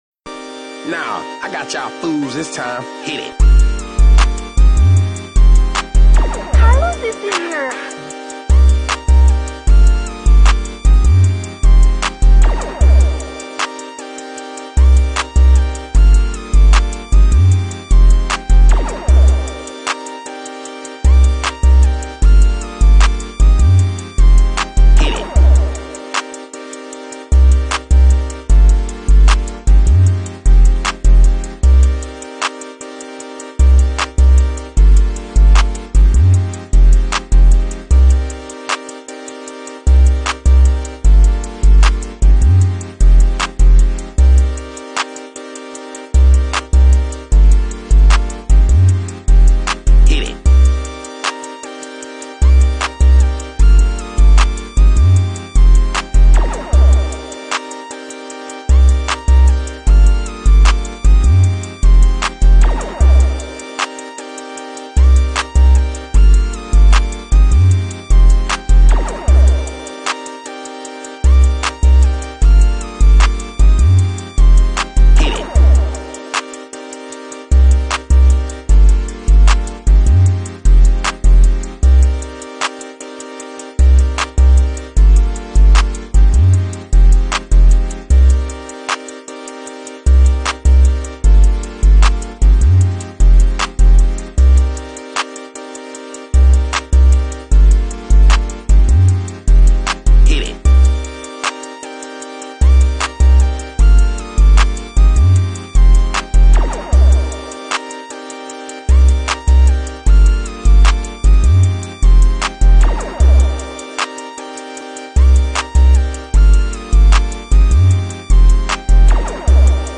Hip-Hop Instrumentals